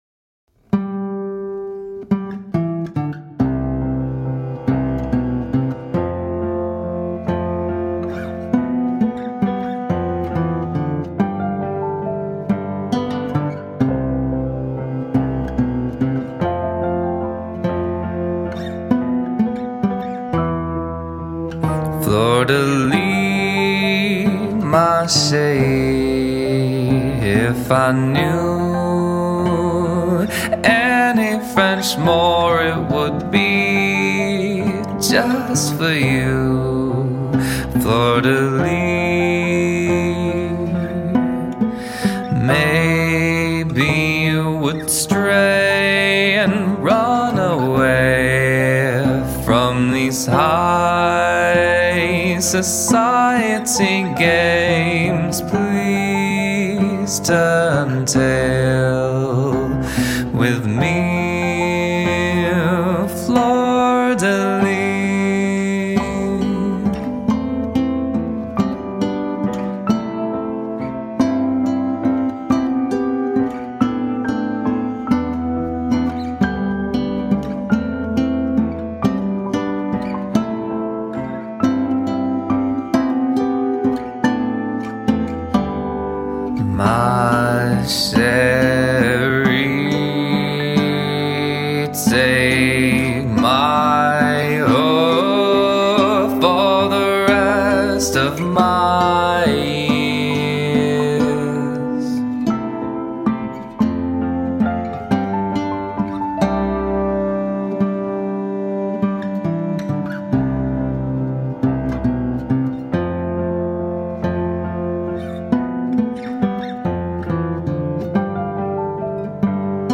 Harmonies were all her, the rest was all me.